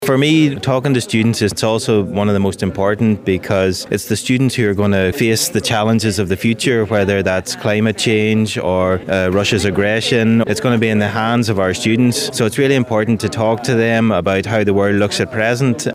Ambasador Irlandii w Polsce Patrick Haughey spotkał się ze studentami Uniwersytetu Marii Curie-Skłodowskiej w Lublinie. Okazją do tego był projekt „Diplomatic Talks”.